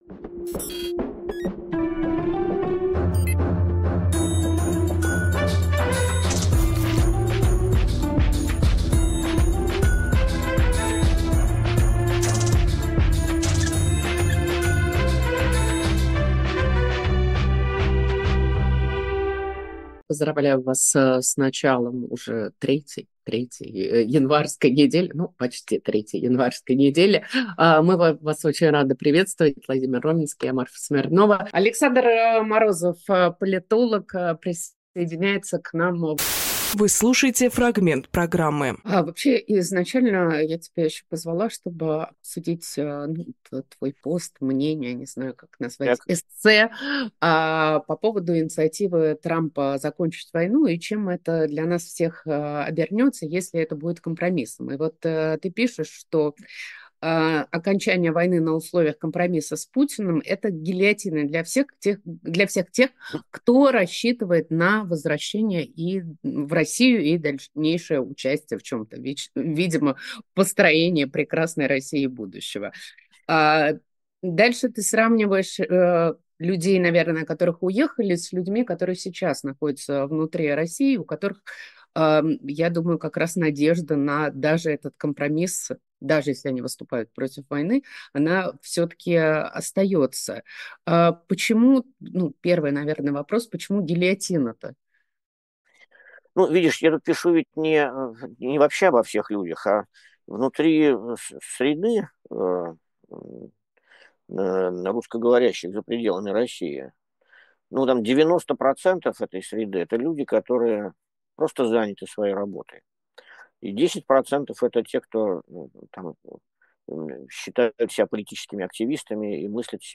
Фрагмент эфира от 20.01.25